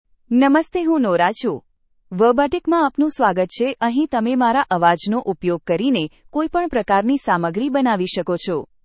NoraFemale Gujarati AI voice
Nora is a female AI voice for Gujarati (India).
Voice sample
Listen to Nora's female Gujarati voice.
Nora delivers clear pronunciation with authentic India Gujarati intonation, making your content sound professionally produced.